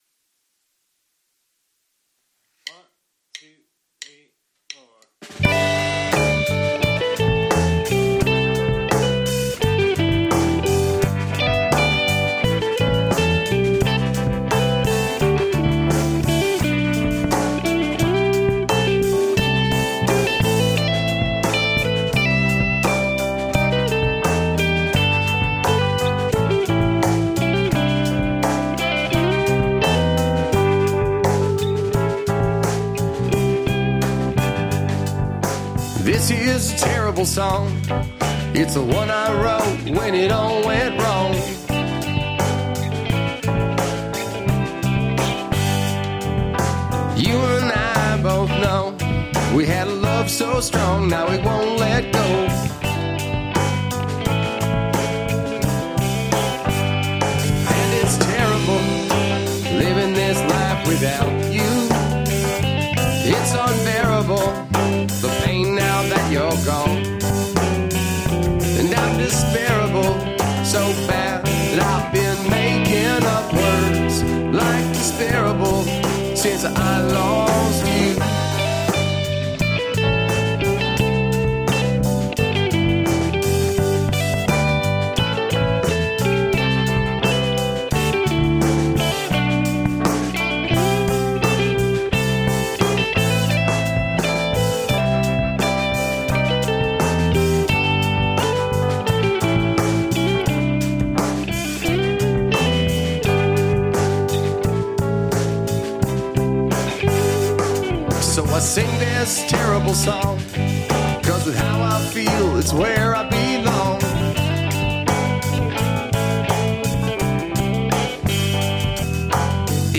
Supposed to mellow rock, kinda like Wilco Sky Blue Sky period.
Kick Drum
Snare Top
Overheads
Bass Guitar
Electric Guitar 1
Rhodes Piano
Vocals
Kick and snare drums have gates as well.
Just MY thoughts...I think the drums seem disjointed from everything else.
It seems way in the back of the room while everything is up front. Also, the lead guitar riff sounds a little too upfront.
It's too loud in the mix and stepping on your vocal in places.